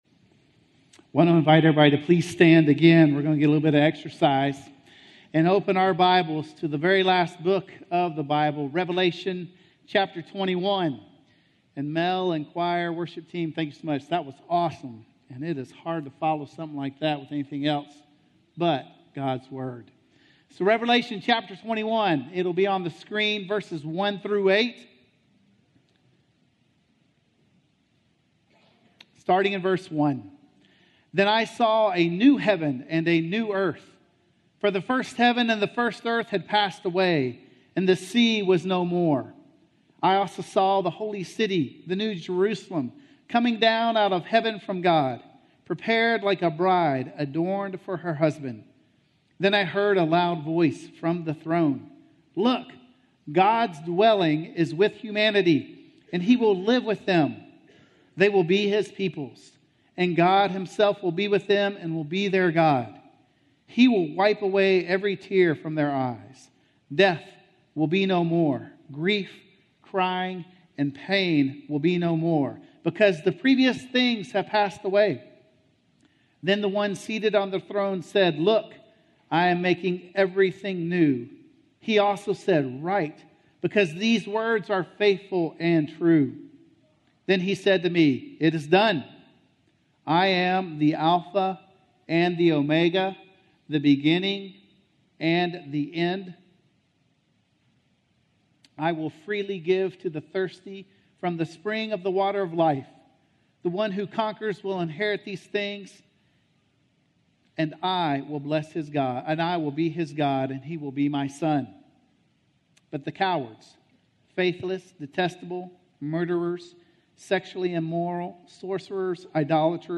All Things New - Sermon - Woodbine